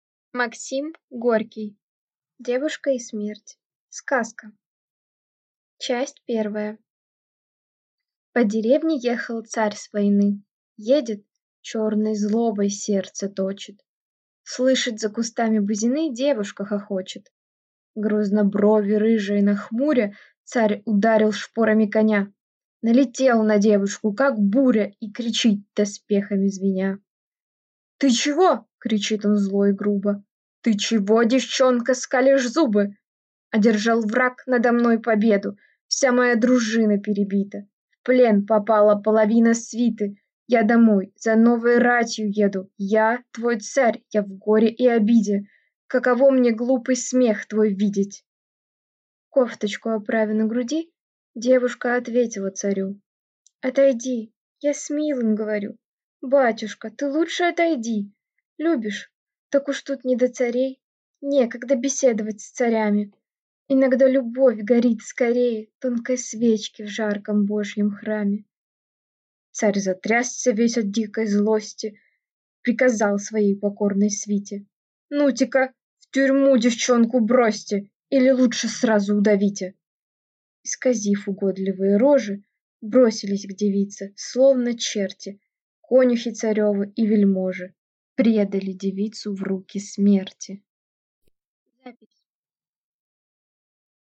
Аудиокнига Девушка и смерть | Библиотека аудиокниг
Прослушать и бесплатно скачать фрагмент аудиокниги